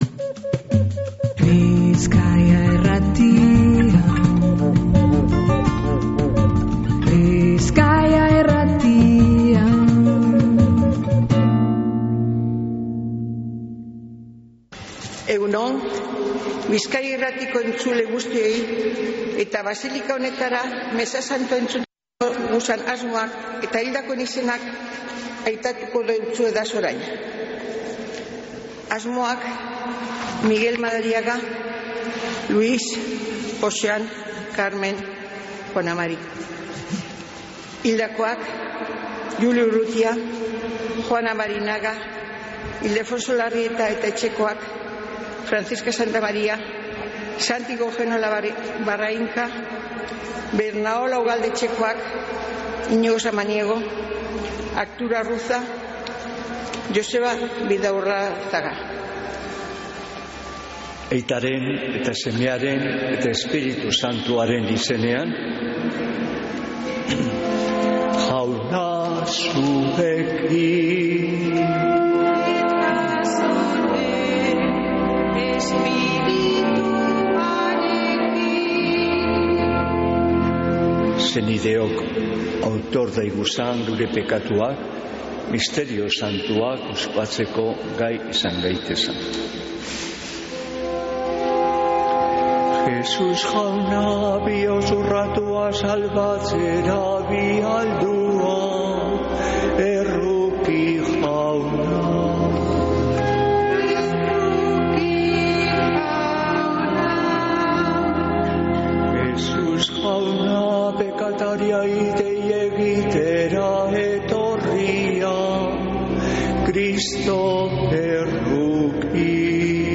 Mezea Begoñako basilikatik | Bizkaia Irratia